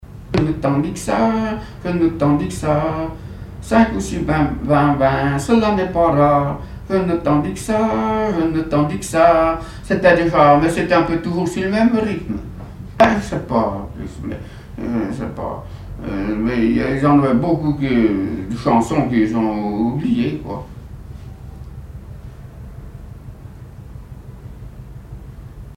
Genre brève
chansons et témoignages parlés
Pièce musicale inédite